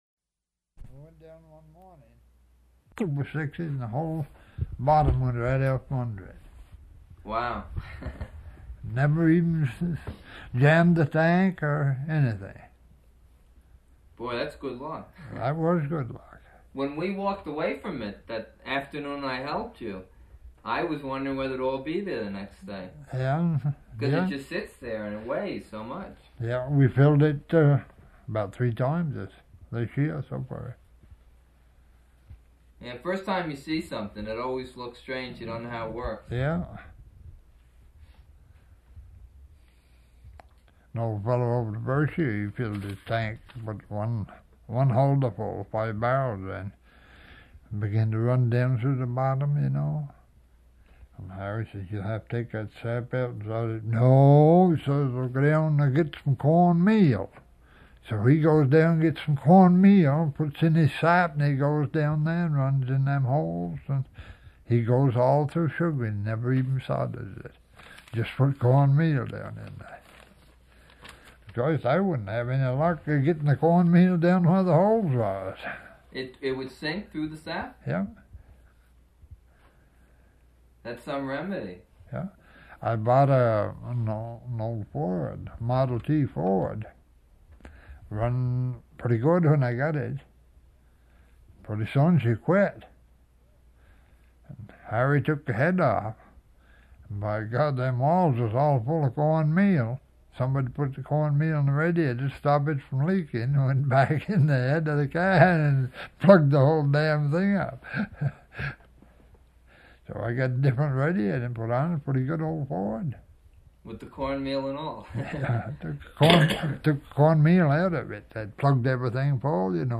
Interview
Format 1 sound tape reel (Scotch 3M 208 polyester) : analog ; 7 1/2 ips, full track, mono.